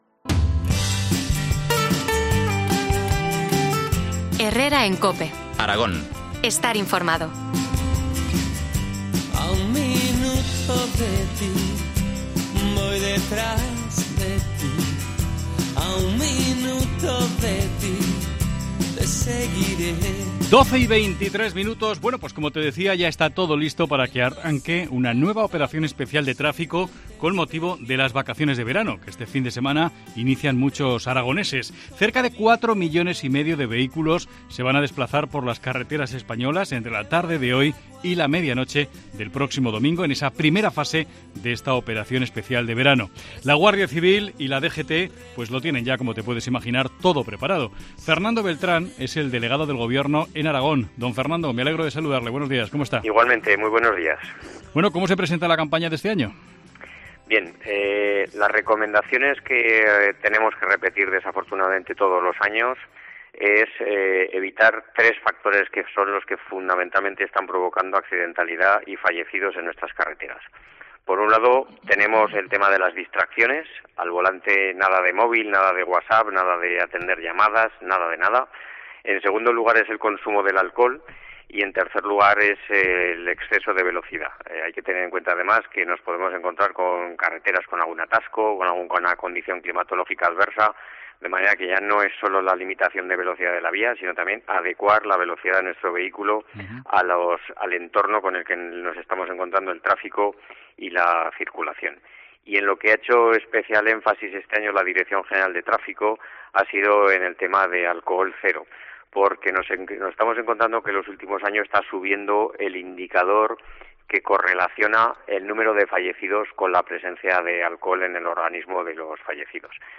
Entrevista al Delegado del Gobierno en Aragón, Fernando Beltrán, sobre la Operación Especial de Tráfico.